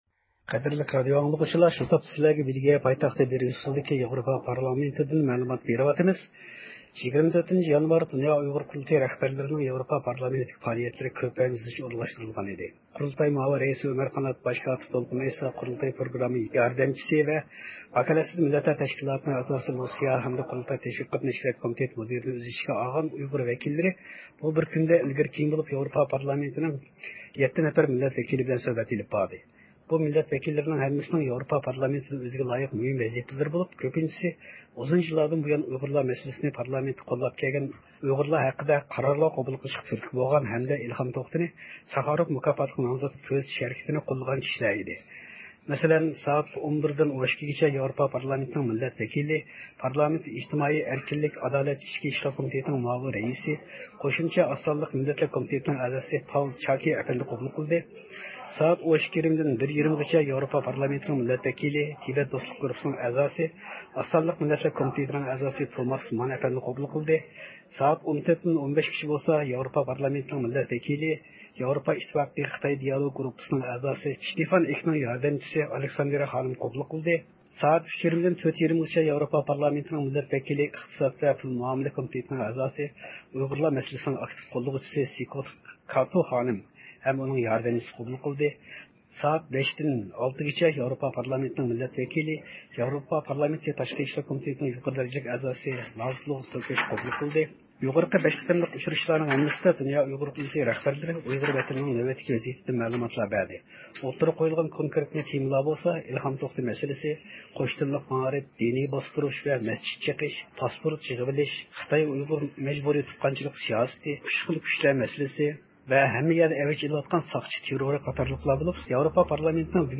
بېلگىيە پايتەختى بىريۇسسېلدىكى ياۋروپا پارلامېنتىدىن مەلۇمات بېرىلدى.